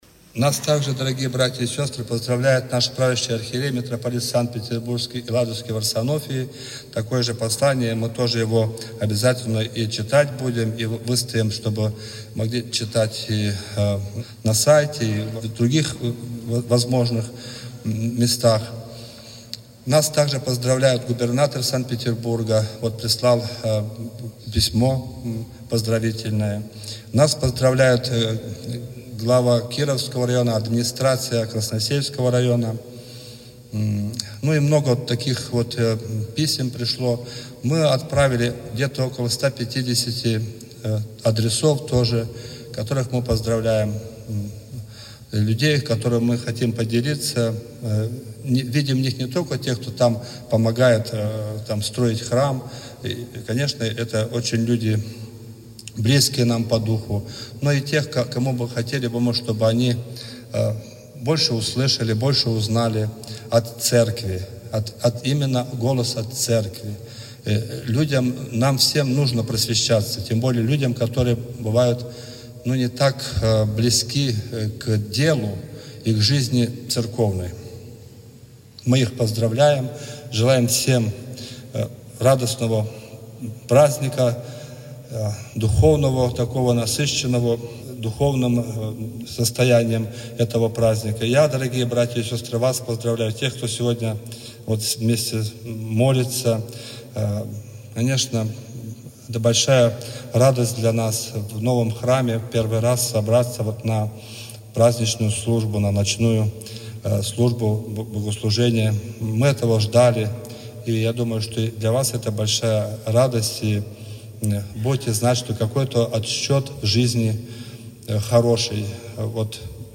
Слово